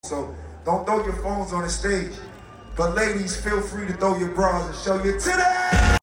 the original audio is from an asap rocky concert)